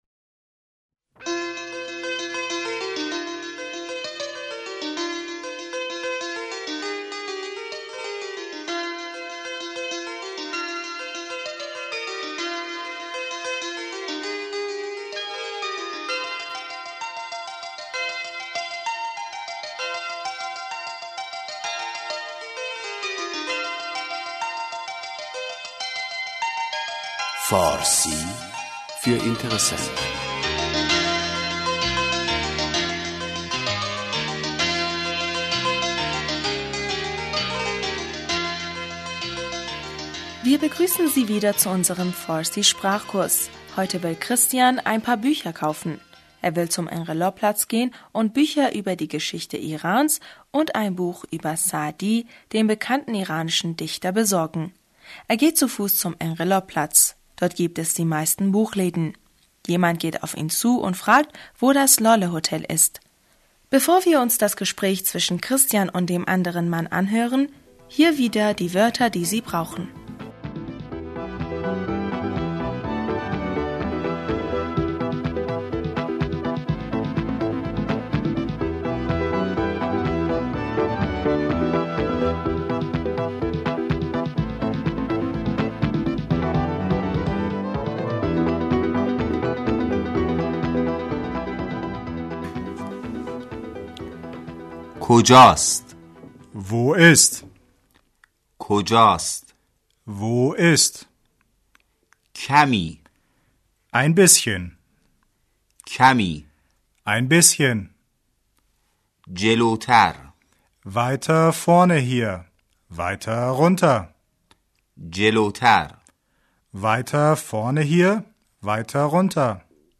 (Sir-seda Straßenlärm und vorübergehende Fußgänger) Der Mann: Guten Tag, mein Herr!